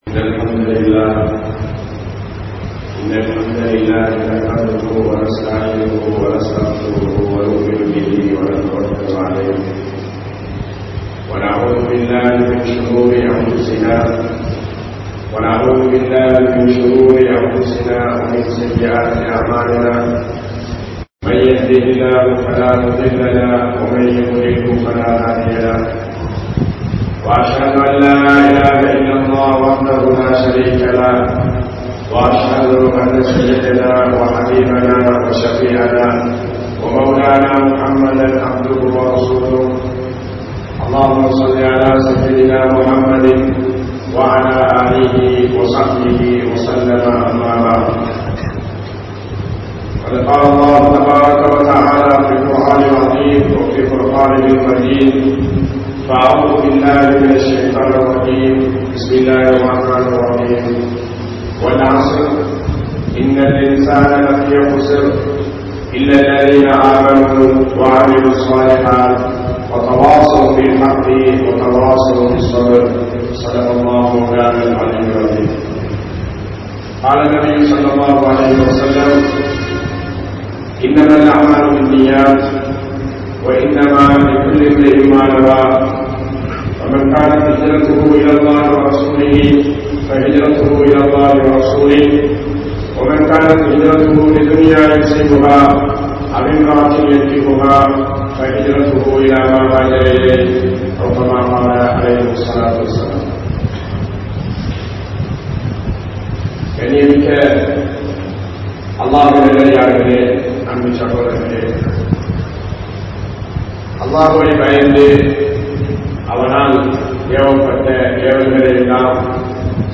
Paakkiyam Entraal Enna? (பாக்கியம் என்றால் என்ன?) | Audio Bayans | All Ceylon Muslim Youth Community | Addalaichenai
Town Jumua Masjidh